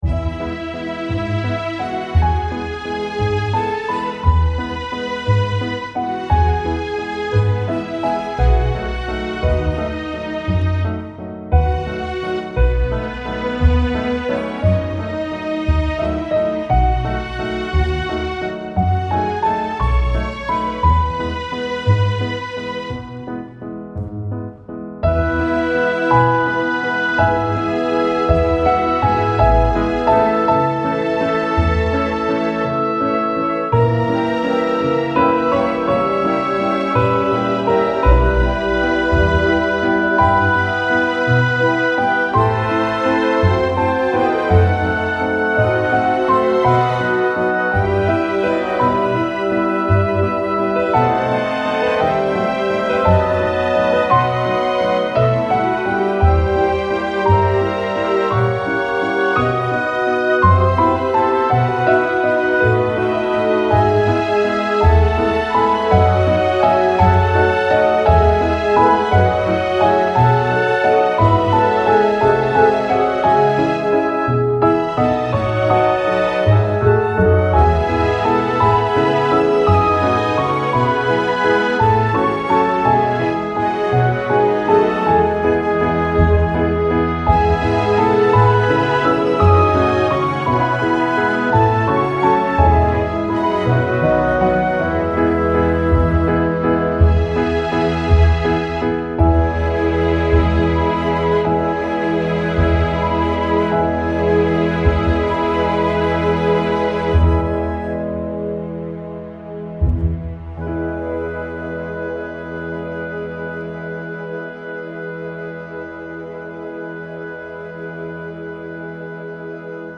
valse - romantique - amoureux - classique - melodique